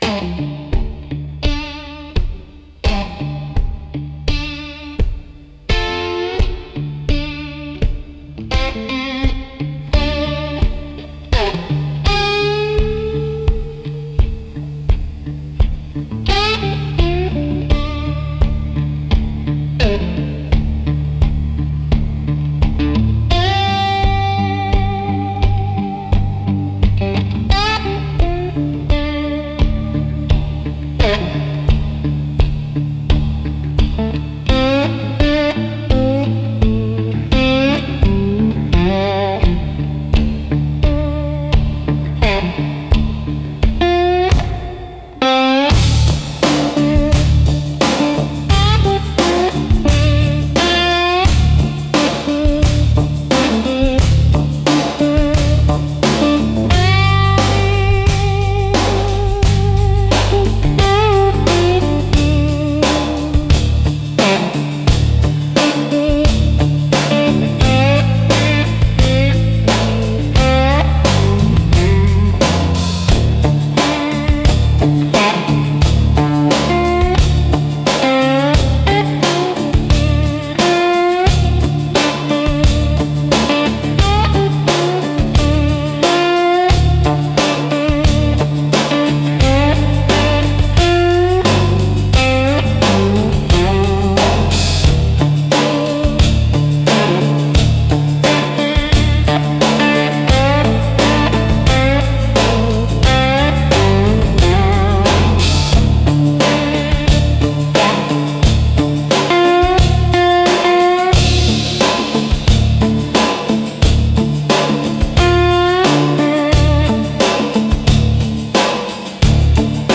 Instrumental - Backwater Redemption